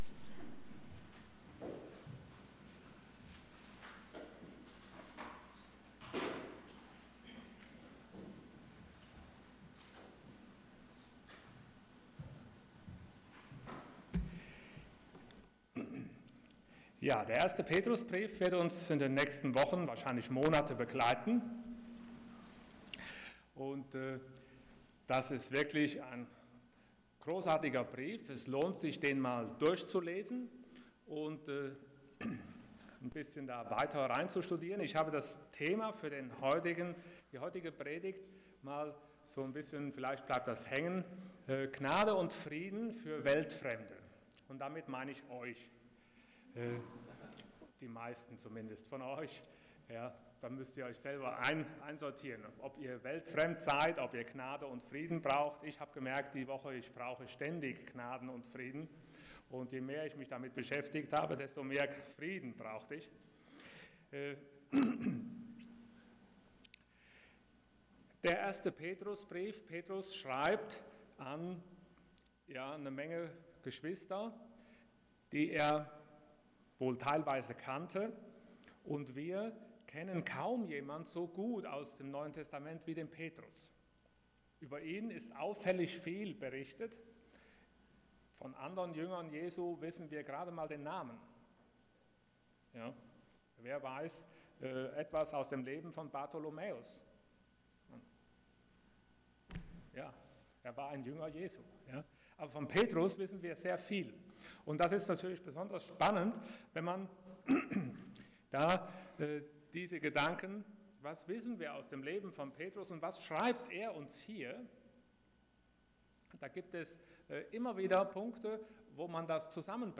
Passage: 1 Peter 1:1-2 Dienstart: Sonntag Morgen